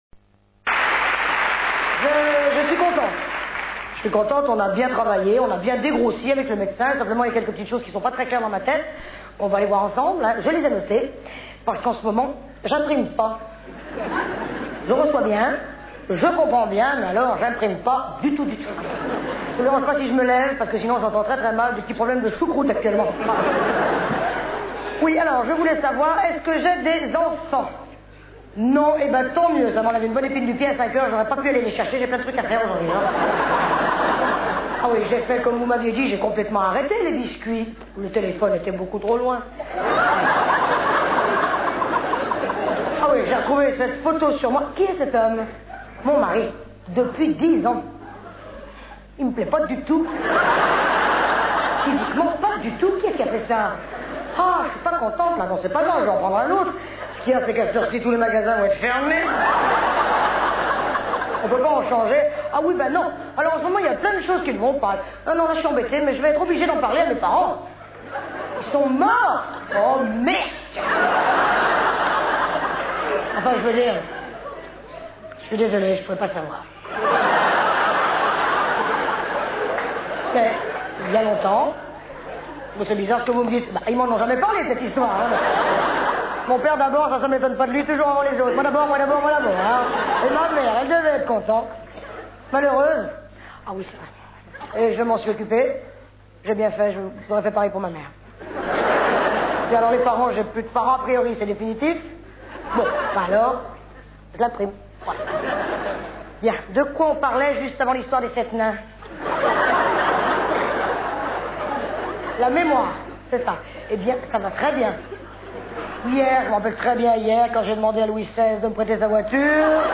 Quelques photos !... et des extraits de spectacle.